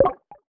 Pinball Game Shoot 2.wav